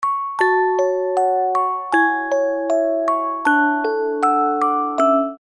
мелодичные
без слов
Музыкальная шкатулка
Прелестная мелодия на оповещения